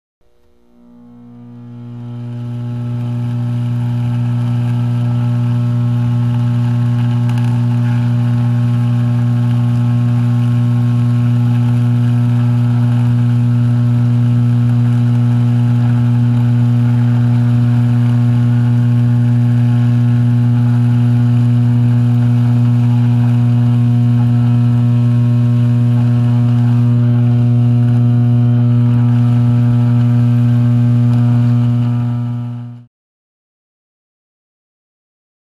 Static; Radio Static Fading In And Out.